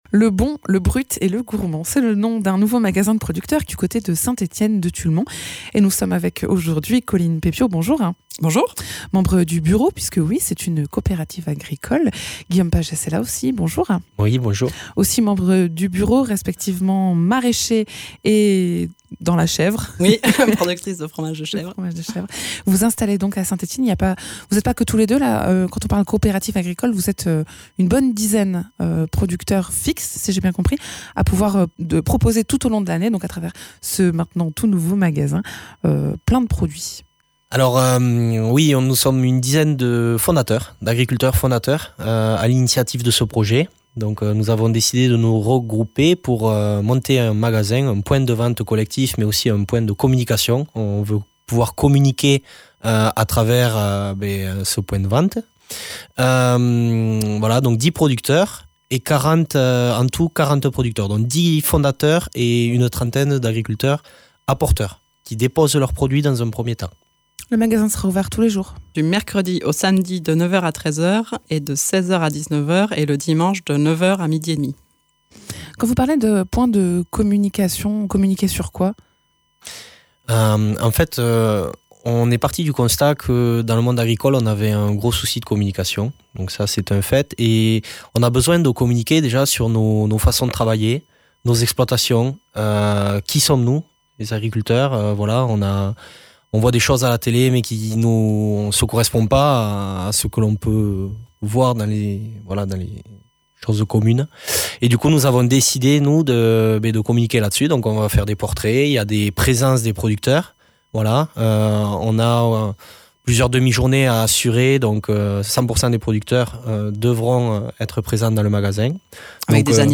Invité(s)